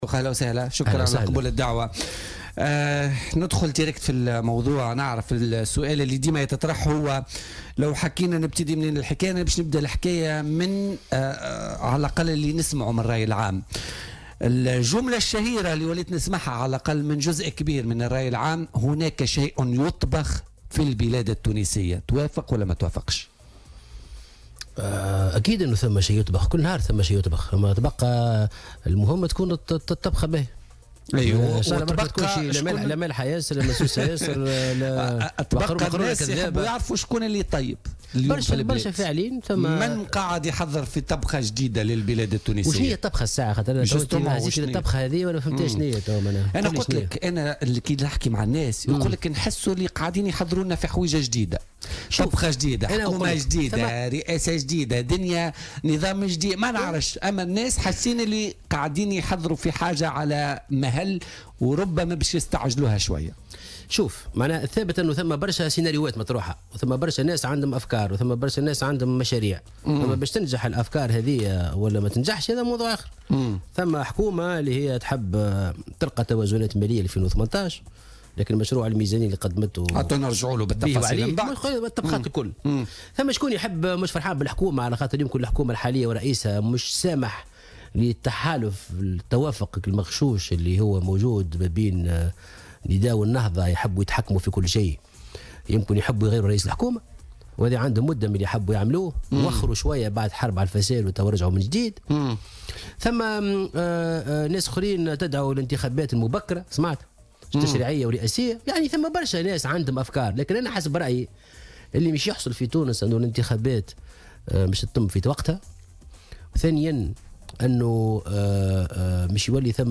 أكد الأمين العام لحركة مشروع تونس محسن مرزوق ضيف بولتيكا اليوم الإثنين 6 نوفمبر 2017 أنه يعتقد أن الانتخابات القادمة ستجرى في أجالها القانونية .